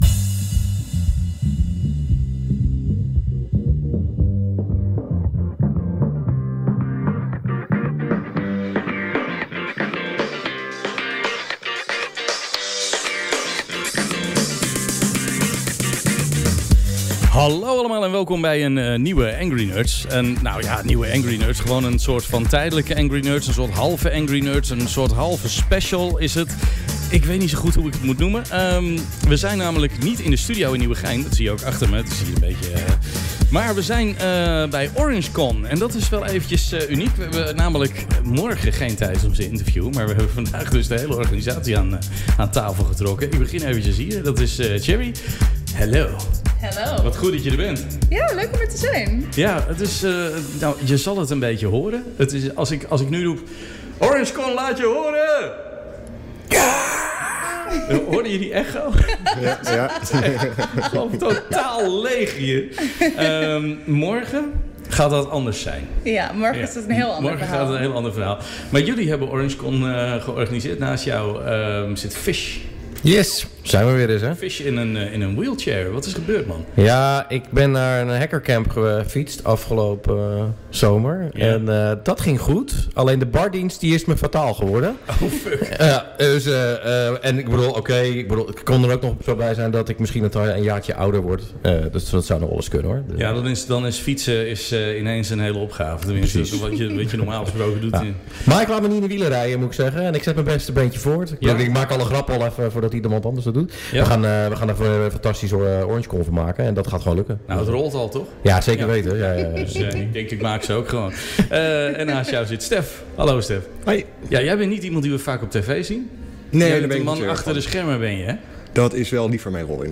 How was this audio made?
1 Angry Nerds - Live op OrangeCon 2025